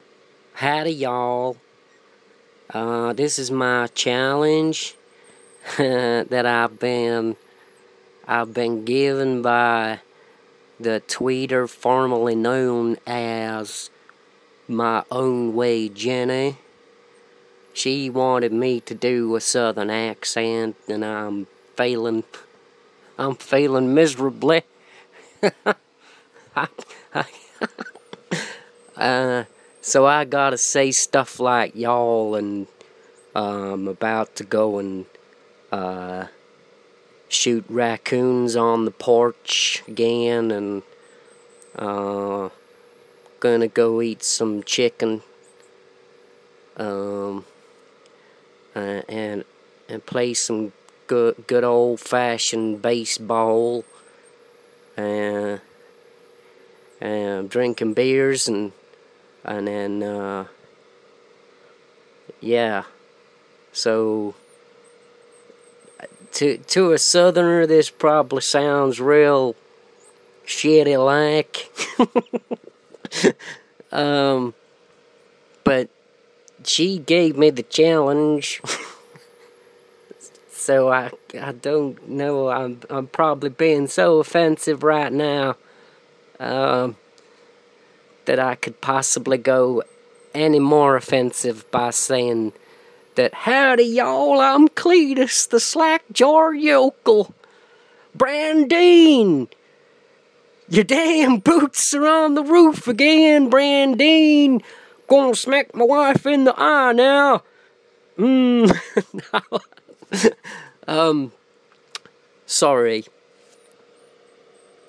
The Southern American accent challenge